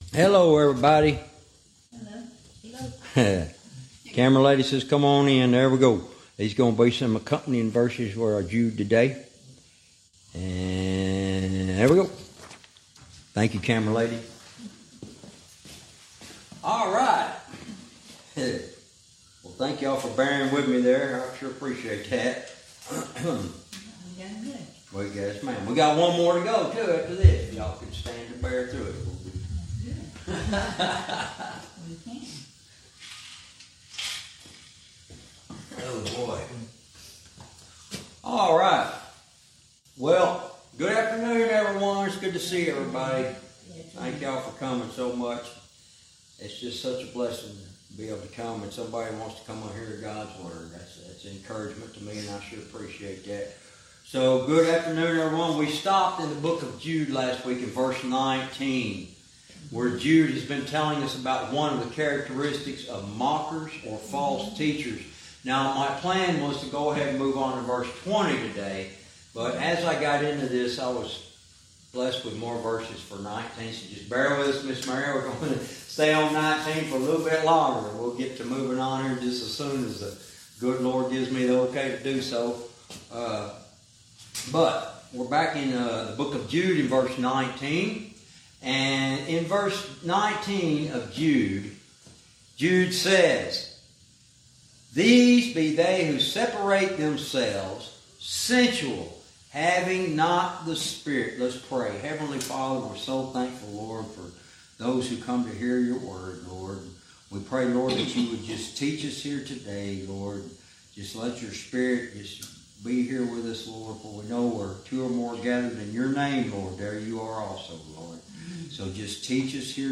Verse by verse teaching - Jude lesson 86 verse 19